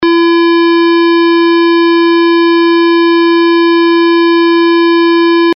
Serie: SIRENAS DE GRAN POTENCIA ACÚSTICA - SIRENAS DIRECCIONALES
45 Sonidos seleccionables - 141dB